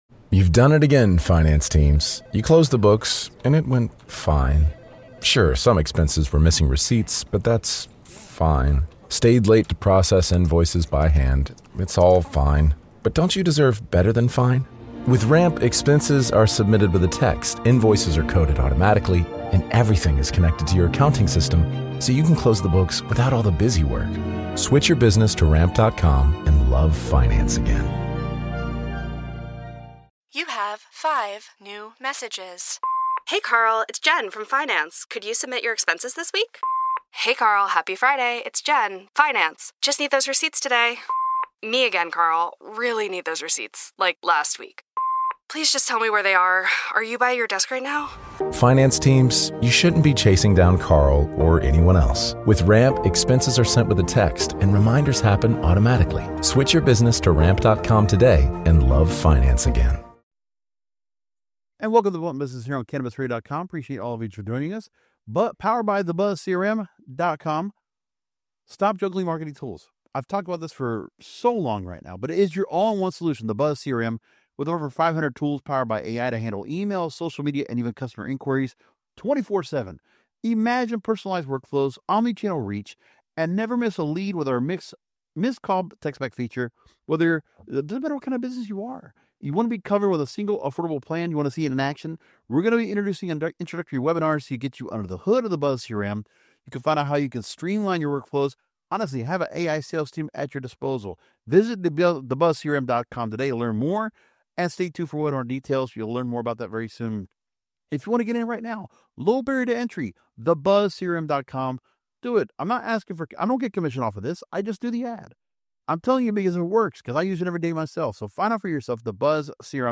Blunt Business will navigate the challenges and opportunities of one of the most profitable industries on earth. Join us each episode to hear our weekly round tables and interviews with industry professionals.